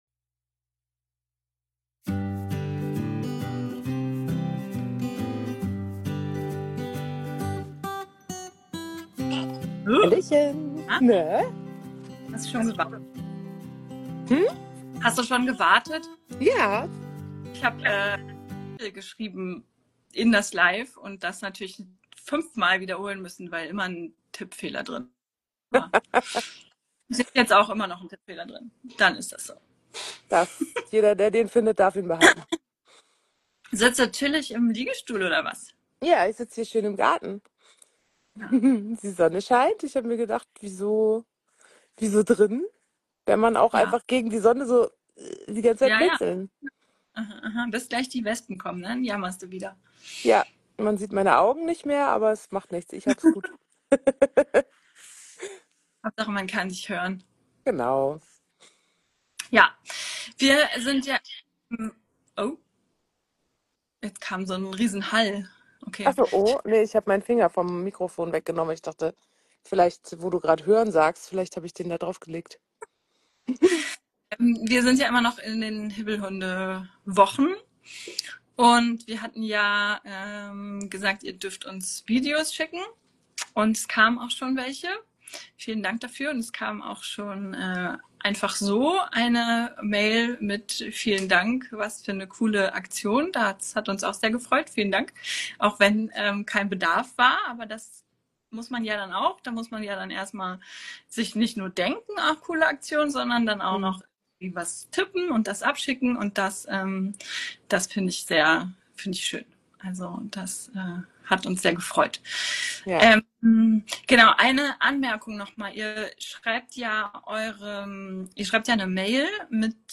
In unserer Live Reihe bei Instagram beleuchten wir das Thema Training mit Hibbelhunden im Moment von allen Seiten. In der ersten Folge ging es um Hunderassen die besonders anfällig dafür sind, besonders aufgeregtes Verhalten zu zeigen, hier könnt ihr die Folge als Podcast anhören.